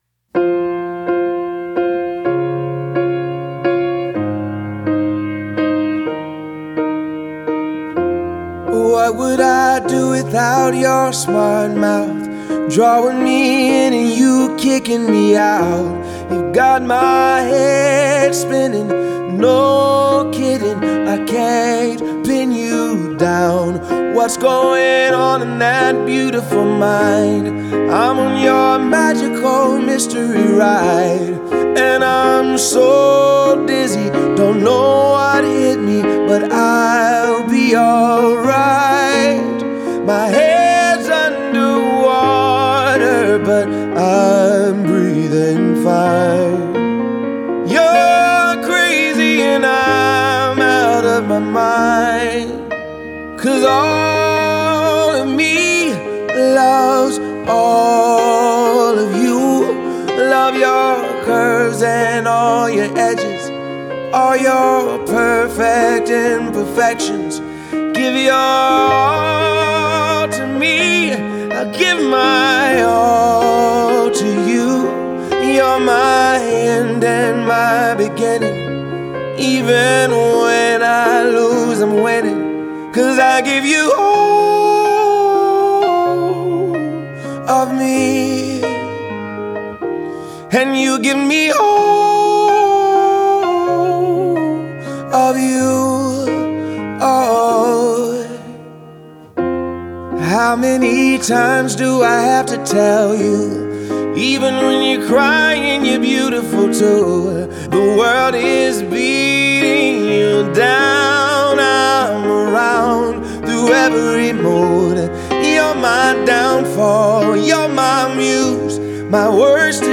موسیقی آرام بخش